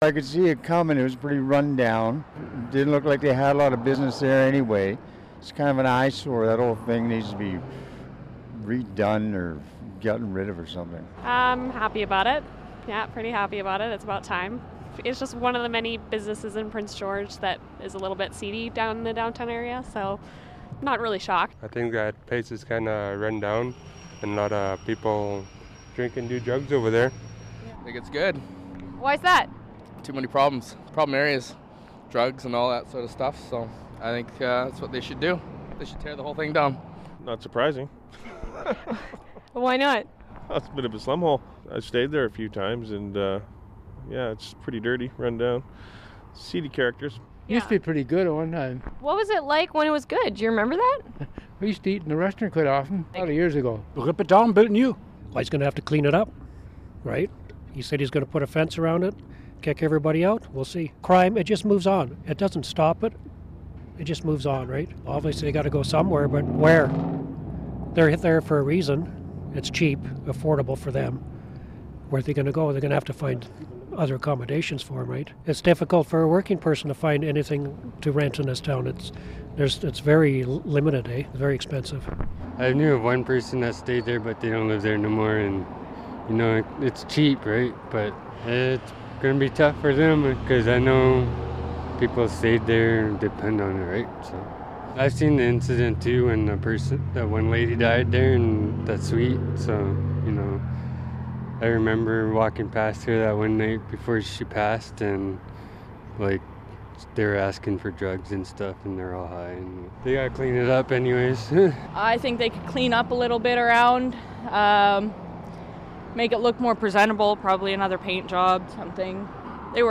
The Connaught Motor Inn will lose its business license for three months this summer-- we speak to the people of Prince George about it.